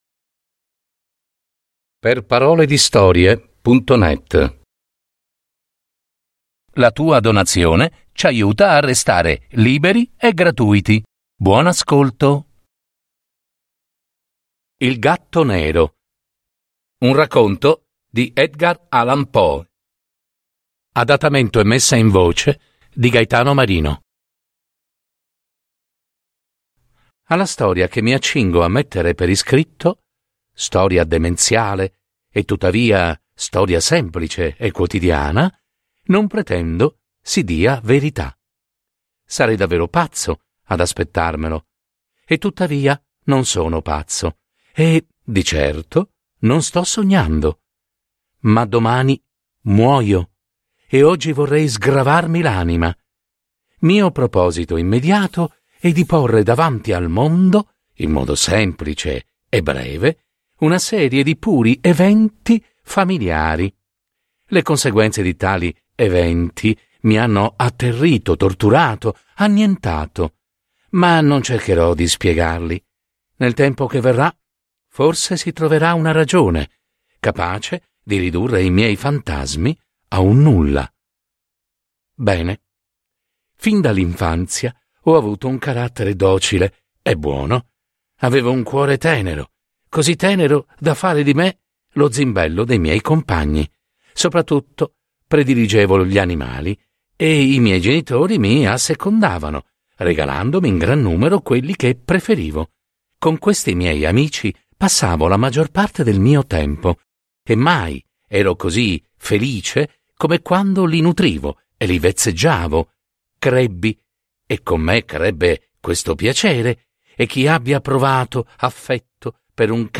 Adattamento e messa in voce